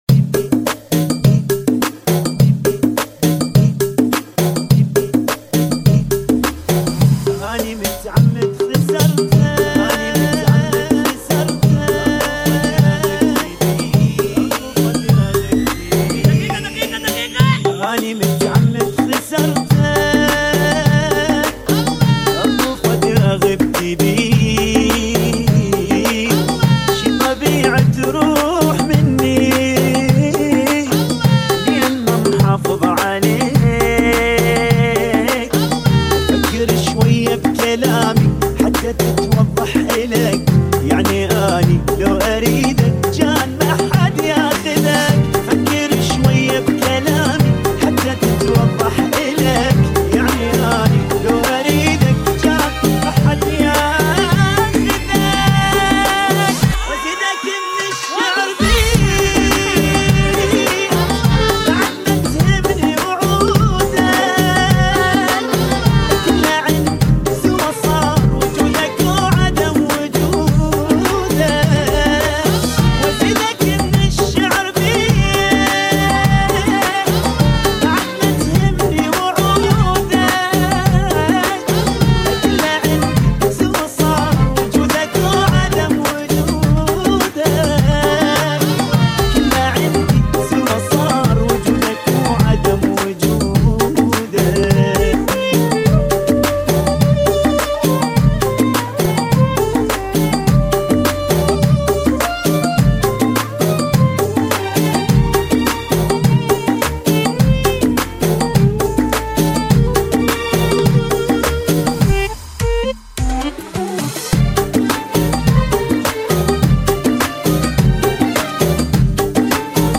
104 Bpm